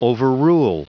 Prononciation du mot overrule en anglais (fichier audio)
Prononciation du mot : overrule